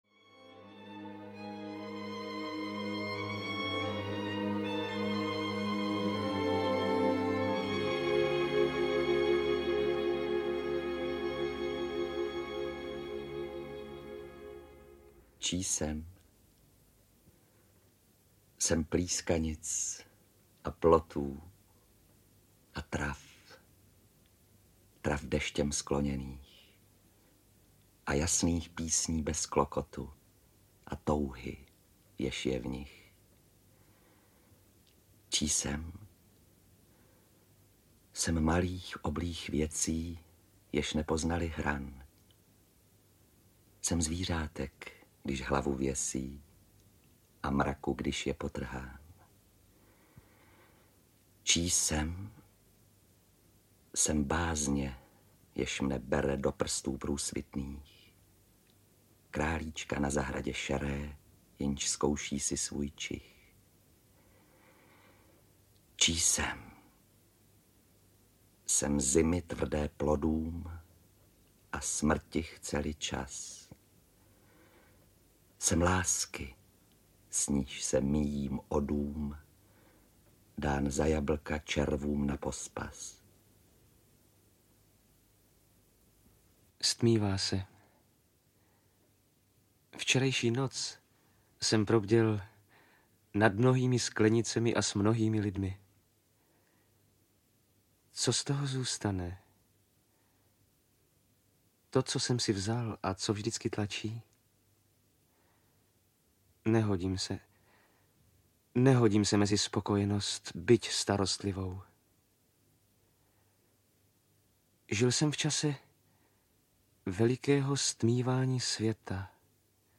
Audio knihaČemu se báseň říká
Ukázka z knihy
• InterpretJaroslava Adamová, Jaroslav Kepka, Ota Ornest, Dagmar Sedláčková, Zdeněk Ornest